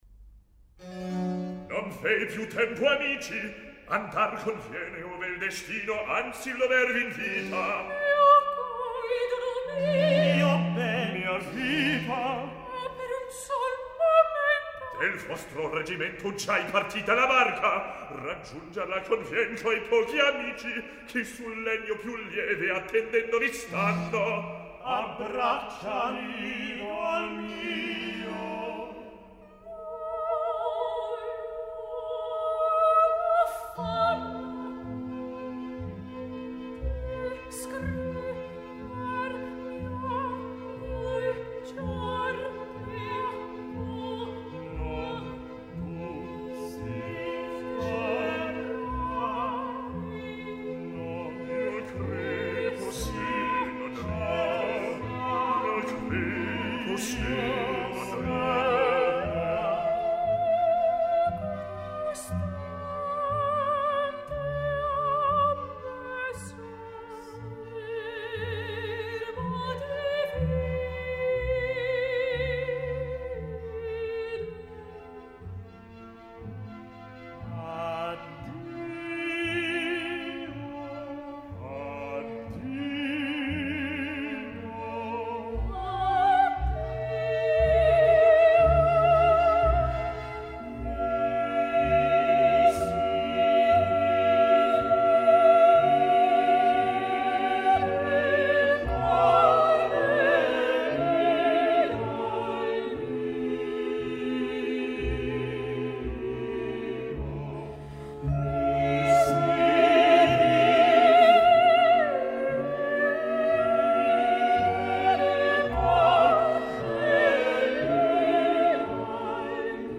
Quintetto